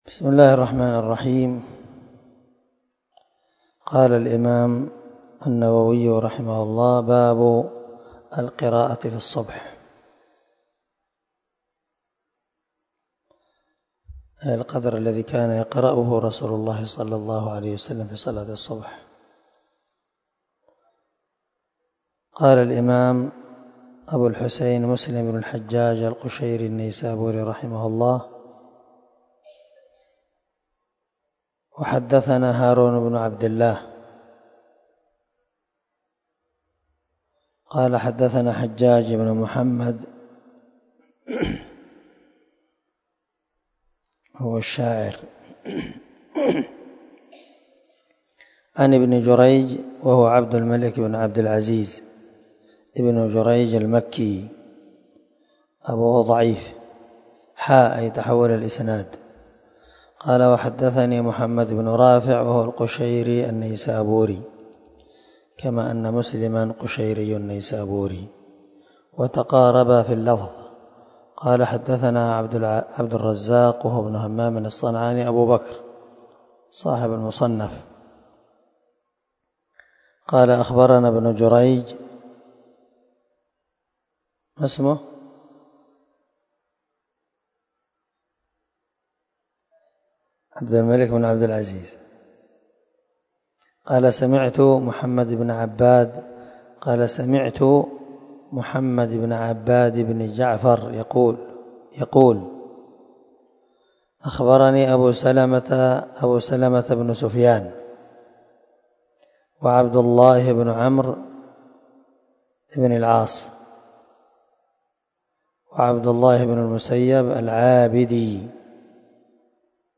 305الدرس 49 من شرح كتاب الصلاة حديث رقم ( 455 – 461 ) من صحيح مسلم